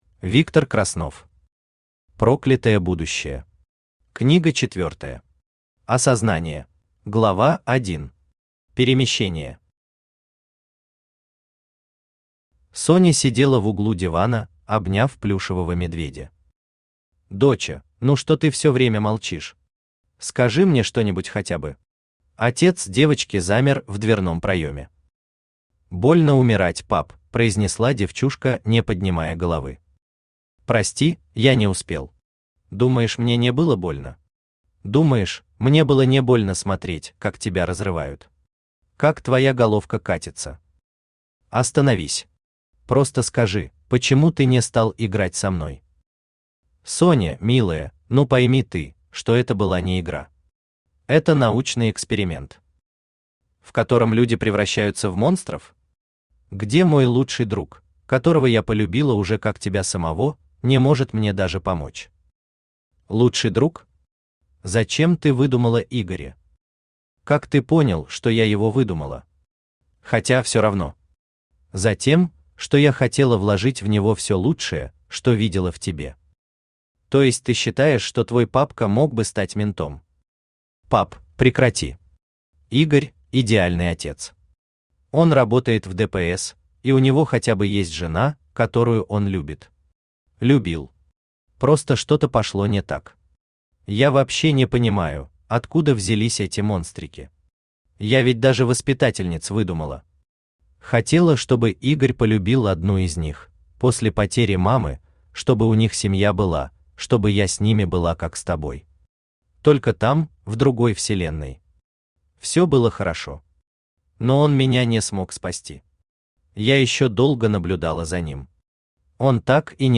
Осознание Автор Виктор Краснов Читает аудиокнигу Авточтец ЛитРес.